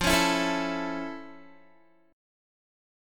Listen to F+M7 strummed